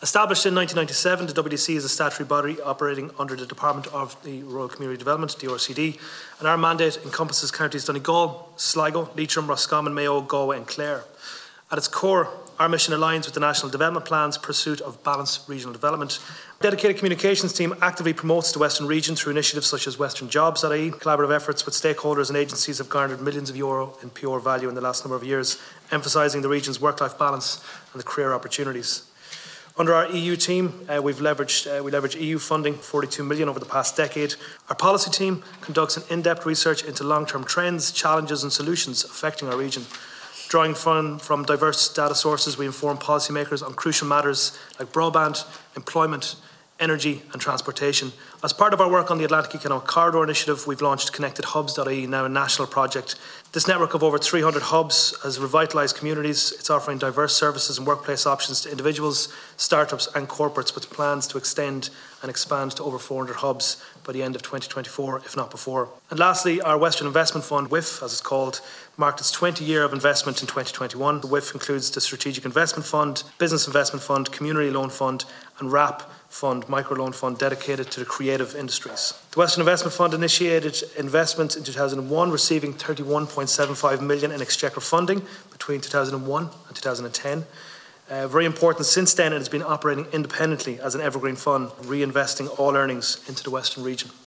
An Oireachtas Committee has been told the Western Development Commission has led to tens of millions of euro in investment across the West and North West over the past 26 years.